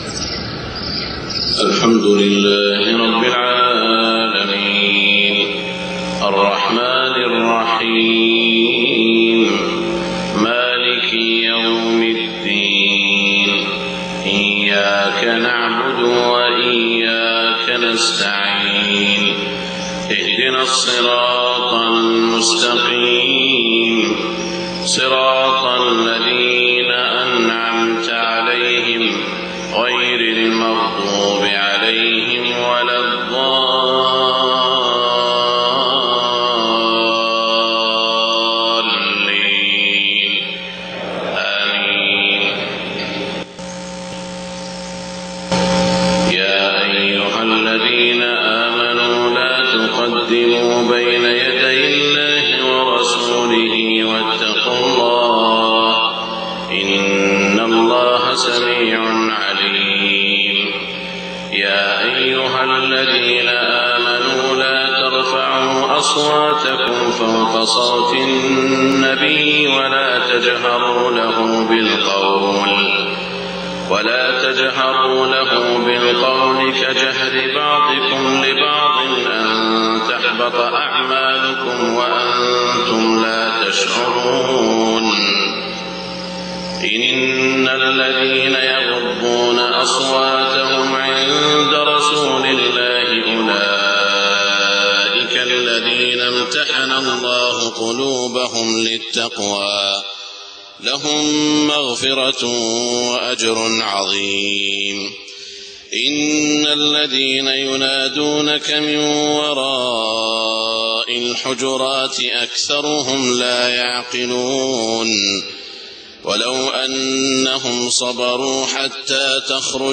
صلاة الفجر 24 ربيع الأول 1430هـ سورة الحجرات > 1430 🕋 > الفروض - تلاوات الحرمين